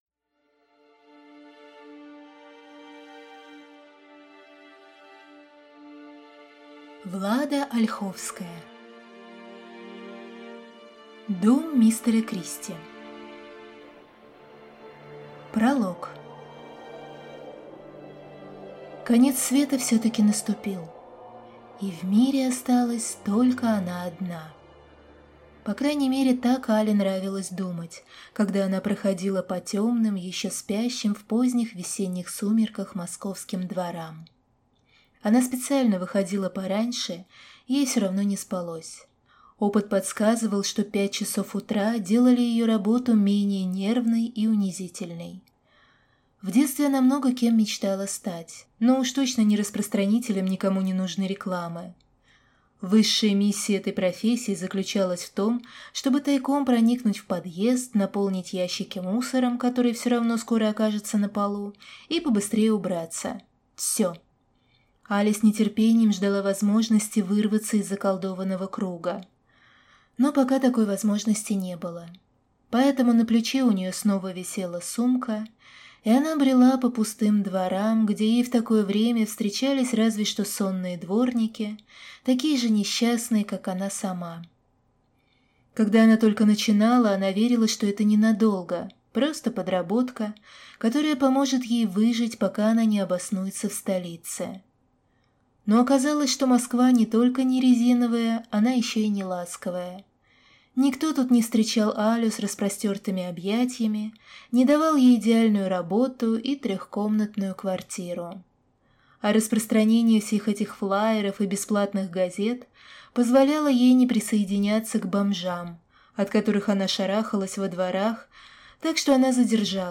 Аудиокнига Дом мистера Кристи | Библиотека аудиокниг